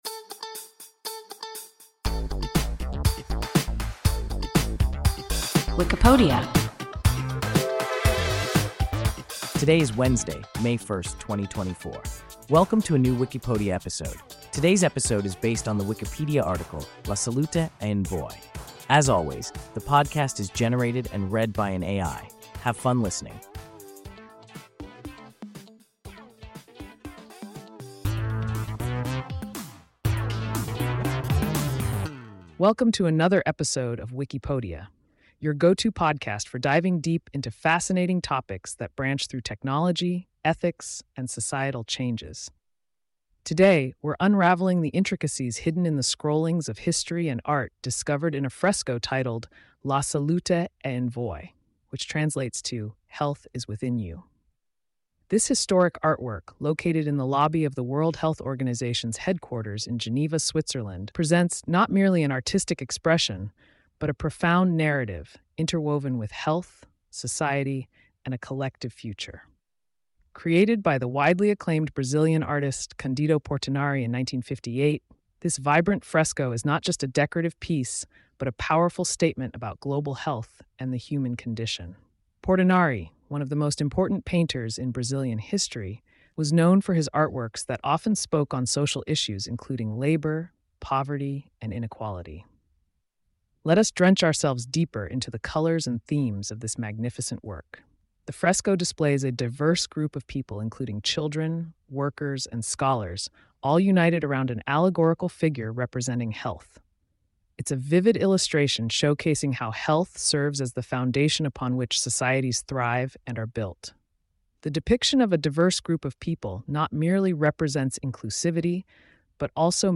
La Salute è in voi – WIKIPODIA – ein KI Podcast